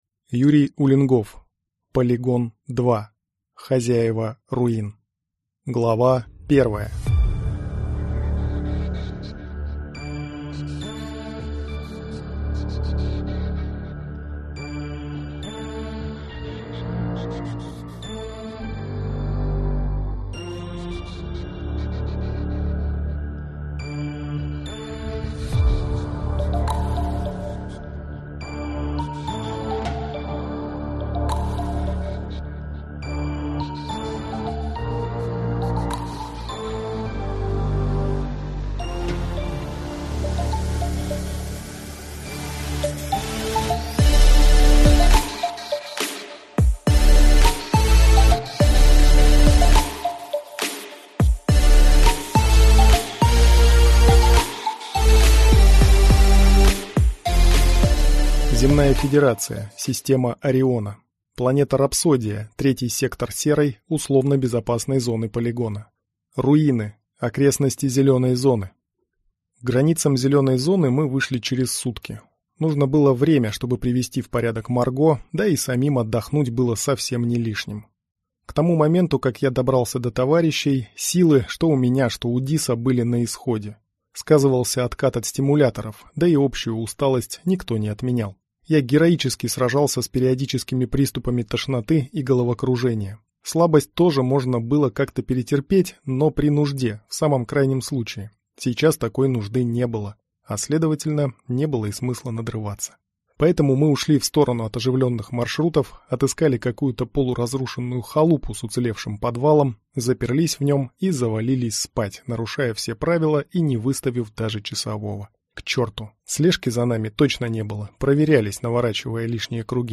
Аудиокнига Полигон-2. Хозяева руин | Библиотека аудиокниг